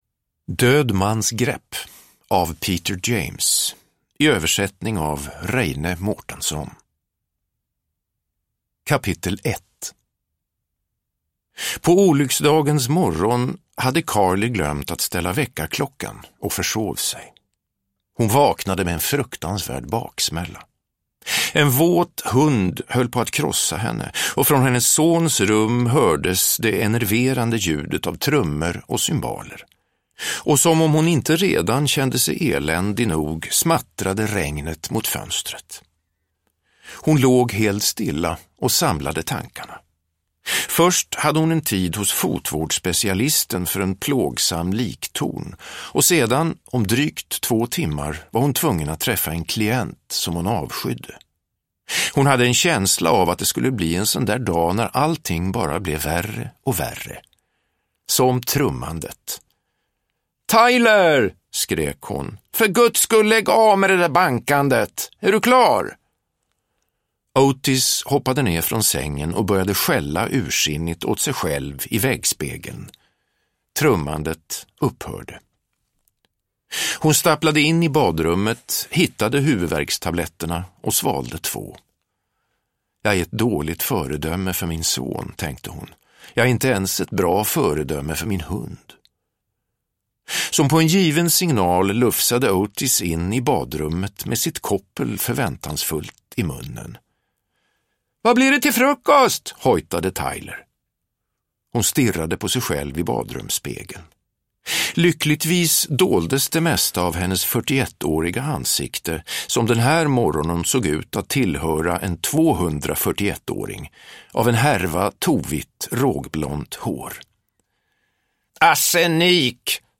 Död mans grepp – Ljudbok – Laddas ner
Uppläsare: Peder Falk